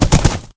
gallop4.ogg